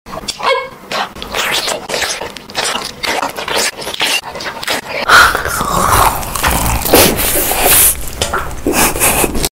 Random pink color food mukbang sound effects free download
Random pink color food mukbang Asmr